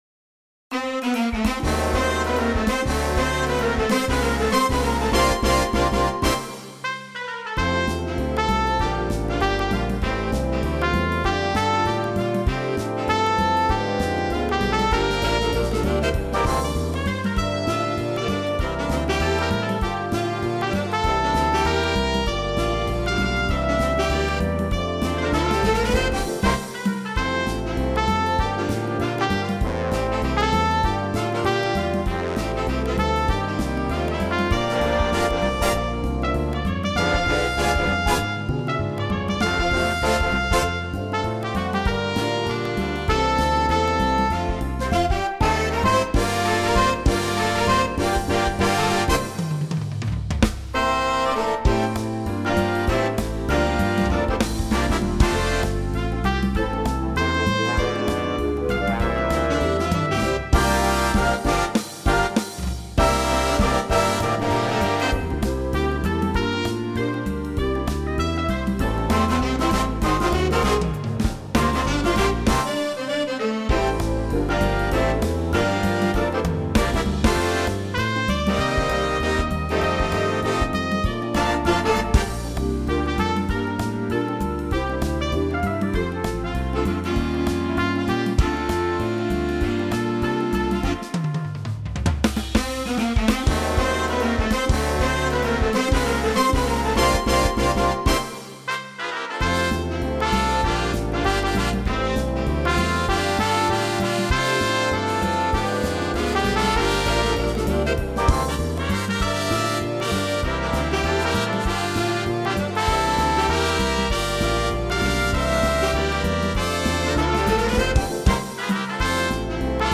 für Blasorchester
Besetzung: Blasorchester